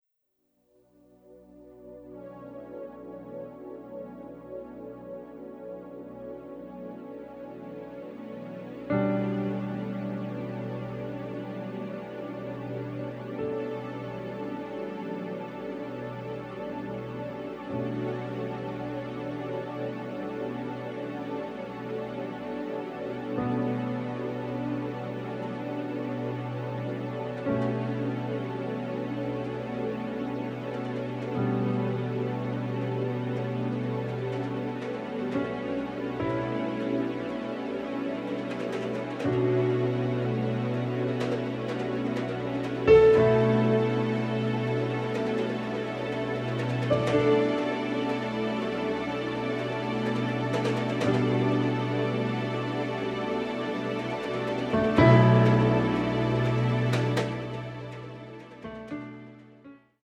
Experimental instrumental music